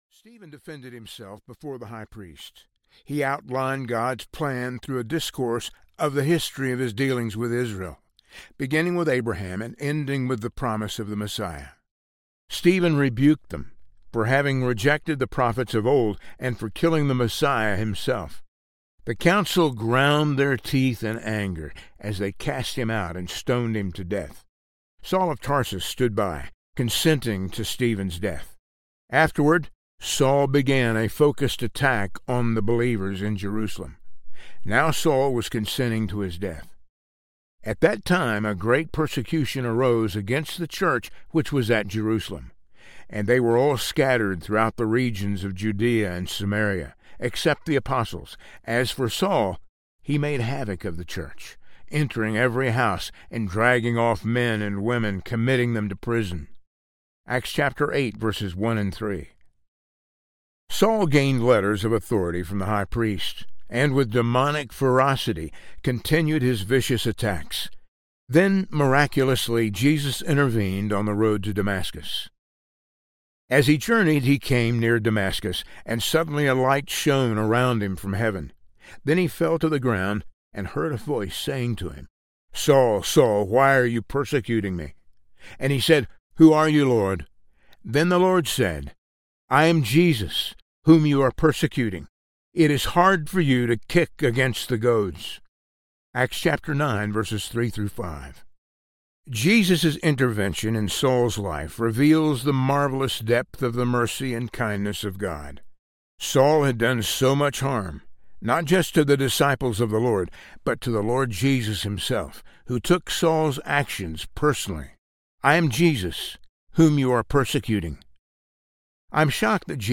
Harbinger of Hope Audiobook